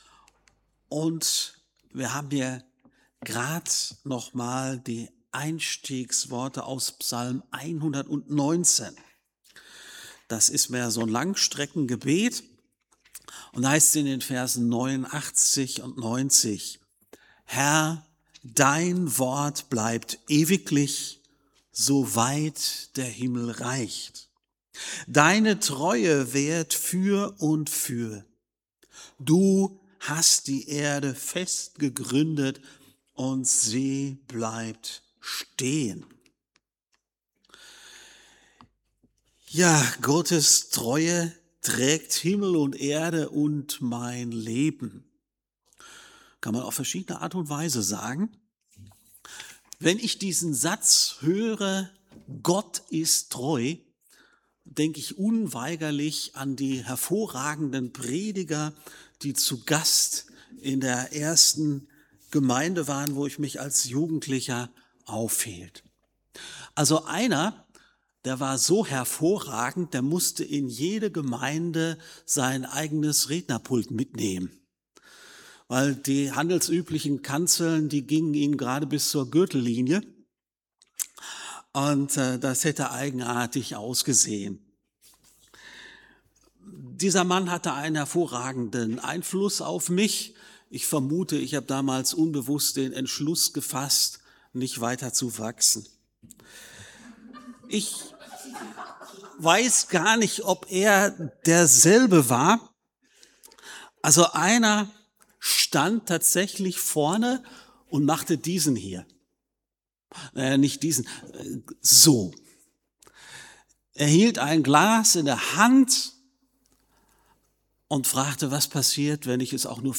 Allianzgebetswoche
FeG Aschaffenburg - Predigt